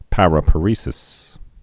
(părə-pə-rēsĭs, -părĭ-sĭs)